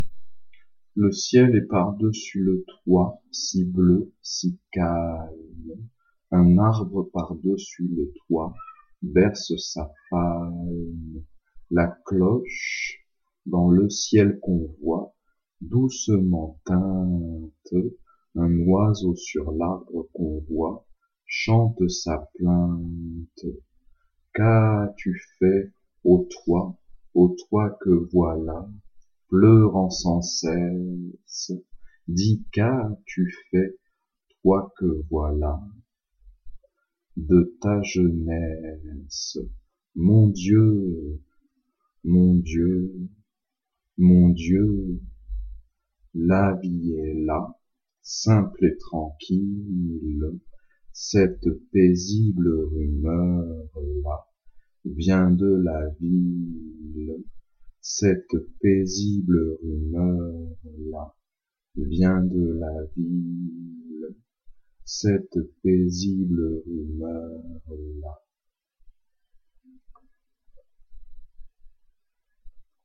You can listen here to the french pronounciation
le-ciel-est-par-dessus-pronunciation.mp3